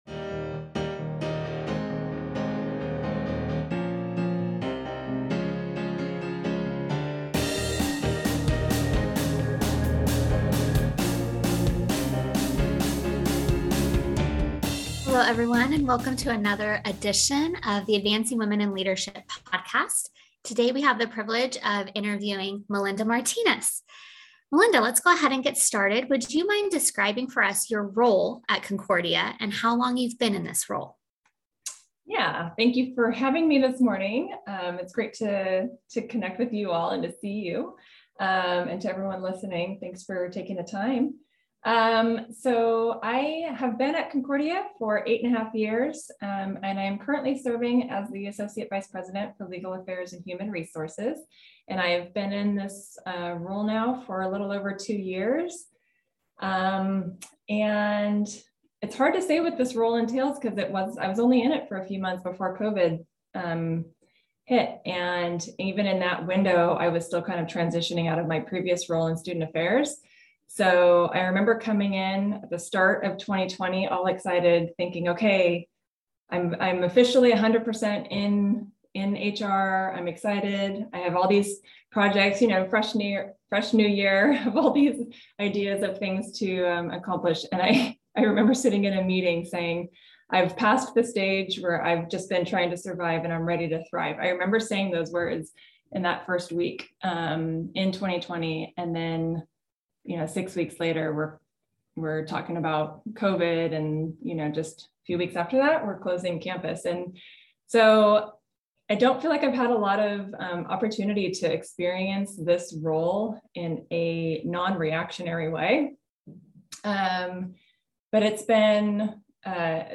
AWiL Interview